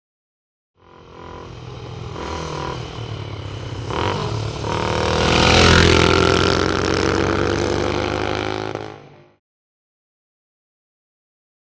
Motorcycle; By; Triumph Twin And B.s.a. Single Up And Past Mic. With Revs.